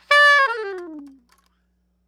SOPRANO FALL
SOP SHRT C#5.wav